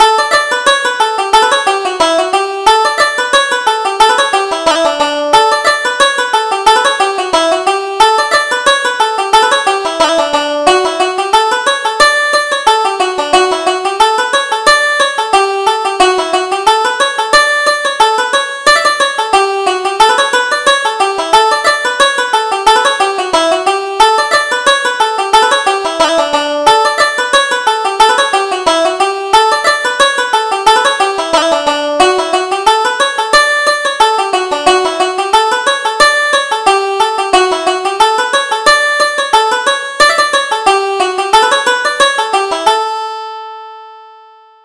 Reel: Casey the Whistler